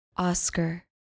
Pronounced: OSS-cah